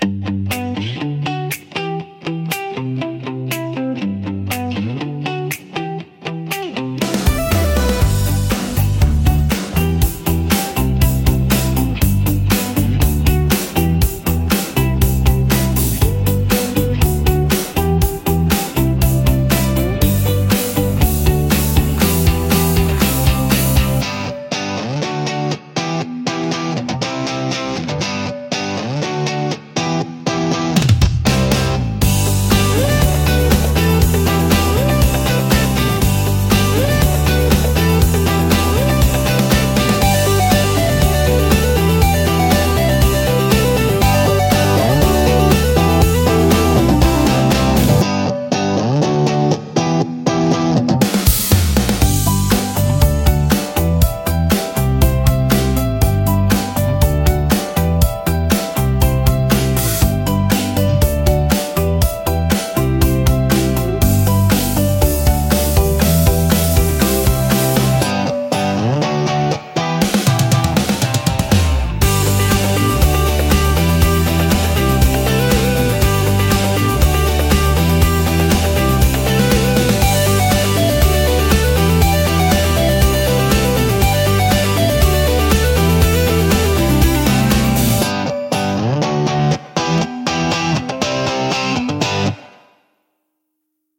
明るく親しみやすい曲調で幅広い層に支持されています。
感情の高まりやポジティブな気分を引き出しつつ、テンポ良く軽快なシーンを盛り上げる用途が多いです。